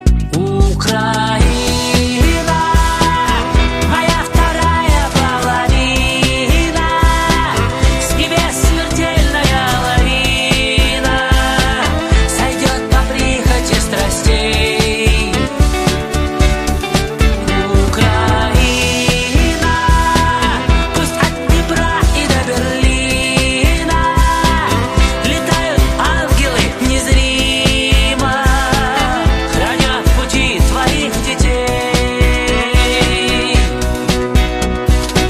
патриотические
воодушевляющие